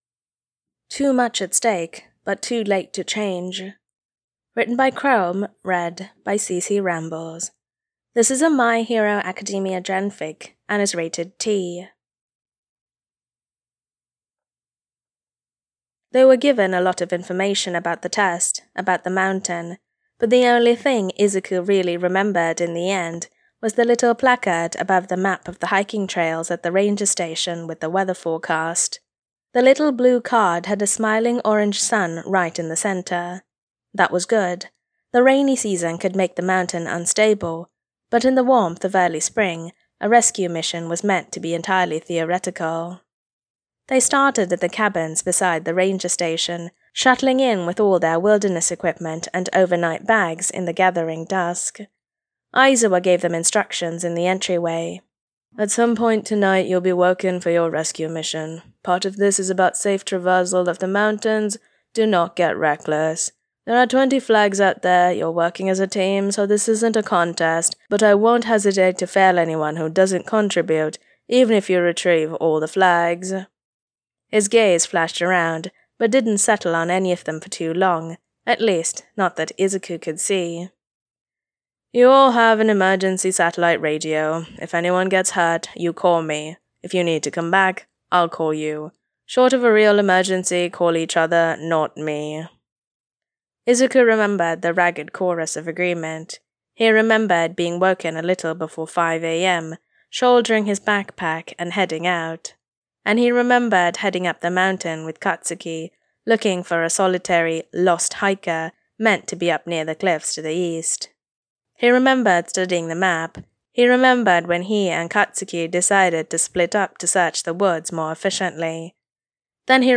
[Podfic] too much at stake but too late to change